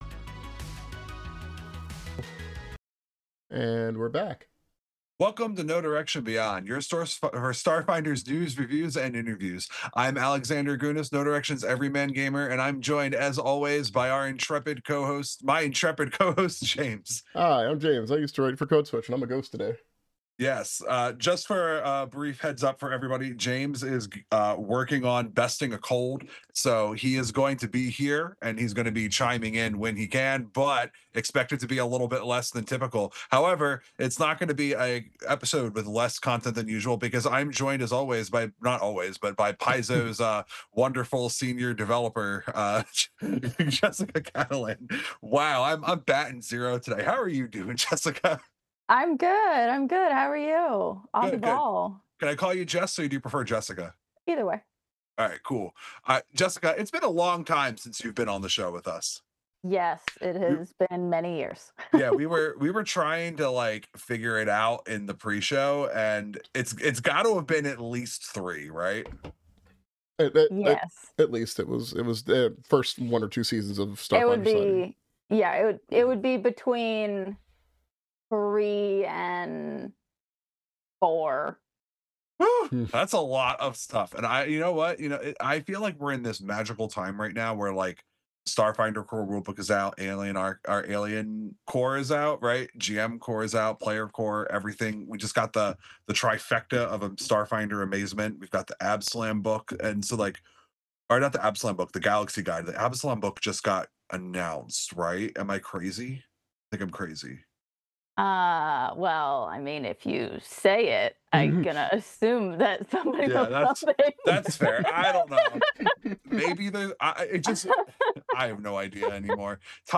Recorded live on Twitch.